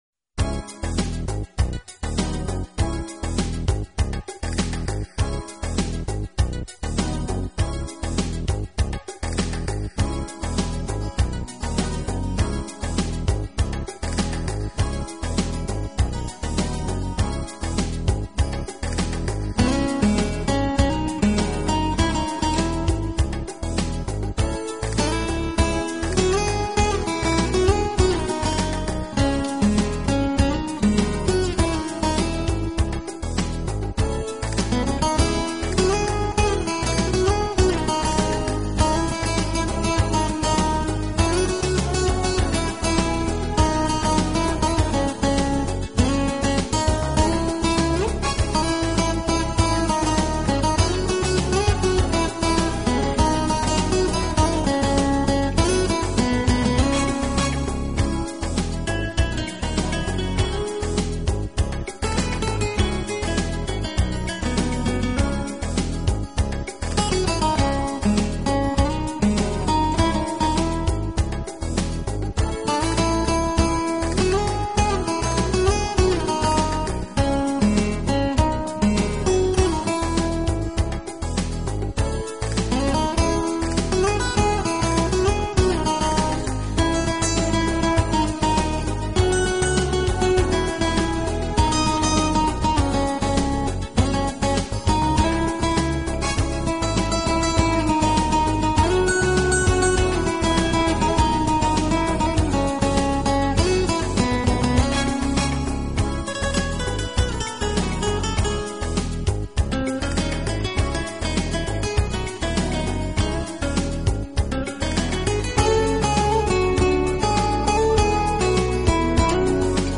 简单而清 新的吉他声，慵懒的节奏，撩动各种怀旧声响制造的甜蜜音符， 让温情声浪激发的百般甜美味觉……